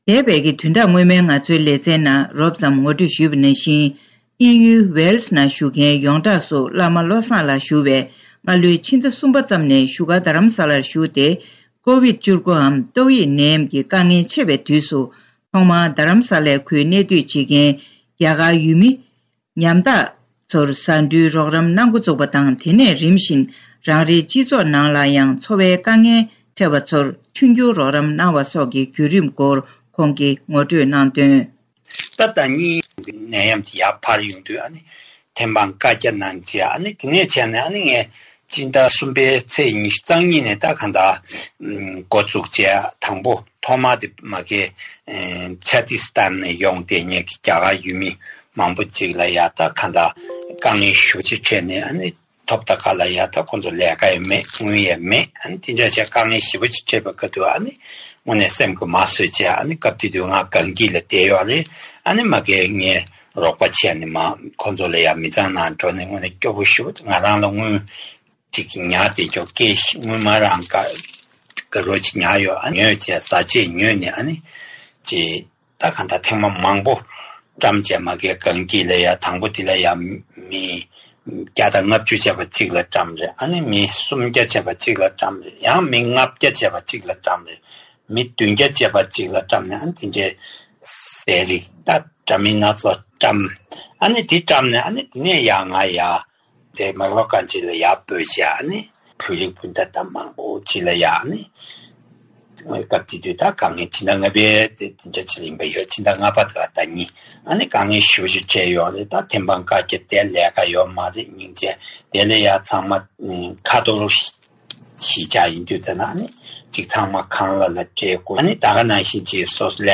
བཀའ་འདྲི་ཞུས་ཏེ་ཕྱོགས་བསྒྲིགས་ཞུས་པ་ཞིག་གཤམ་ལ་སྙན་སྒྲོན་ཞུས་གནང་གི་རེད།